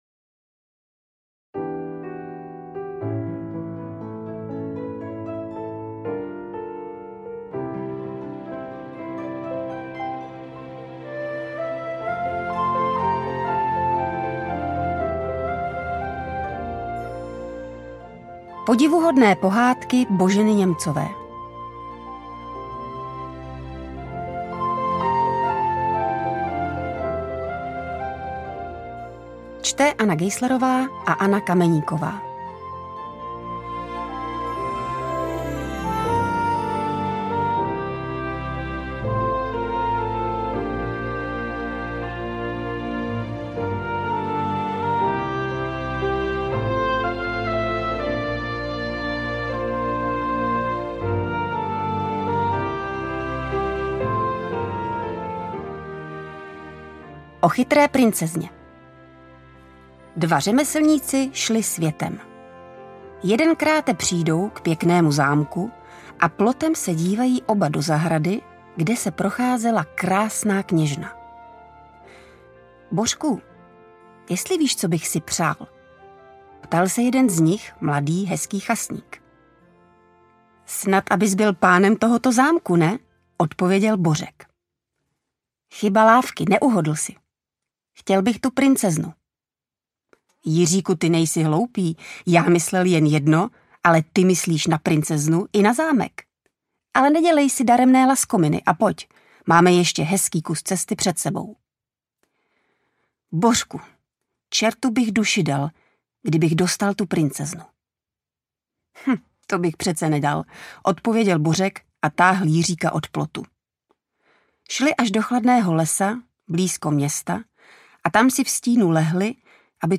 Audiokniha
Obě interpretky Aňa Geislerová a Anna Kameníková jsou s Boženou Němcovou spojeny jako její představitelky ze čtyřdílné televizní série Božena.